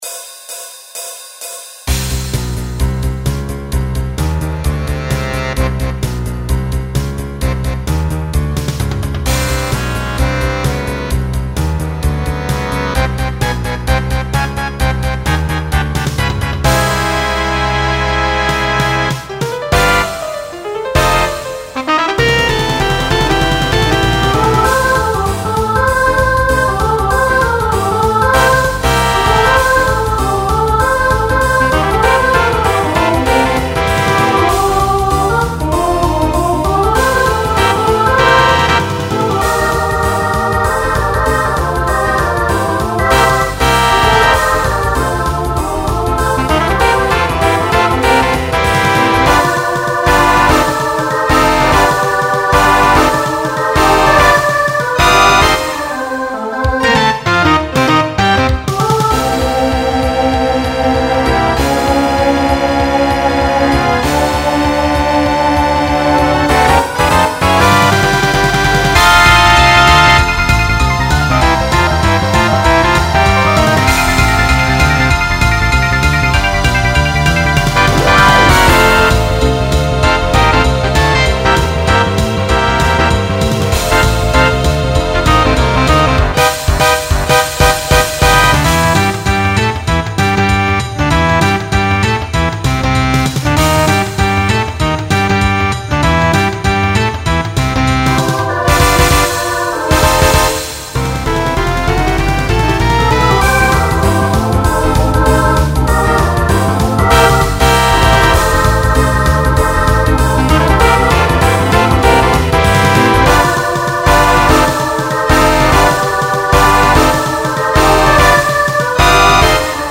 Genre Rock , Swing/Jazz Instrumental combo
Voicing SSA